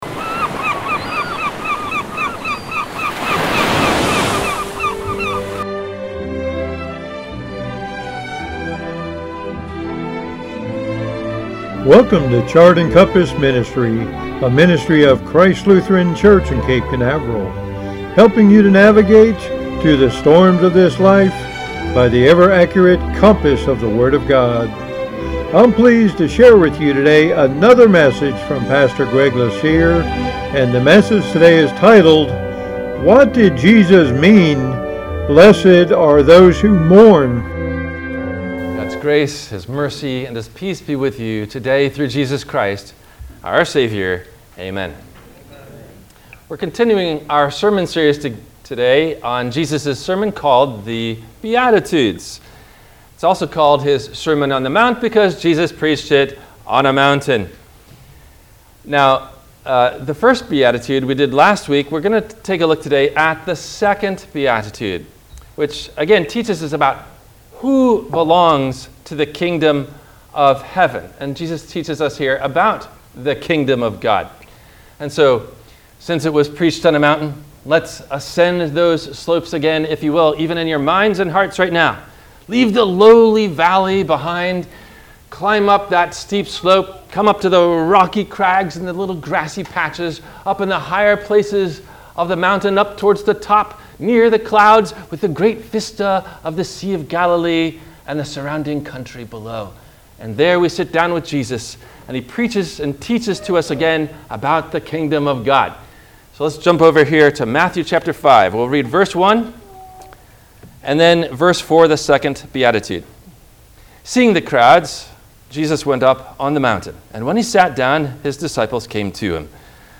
What Did Jesus Mean…Blessed Are You When You Are Persecuted? – WMIE Radio Sermon – September 25 2023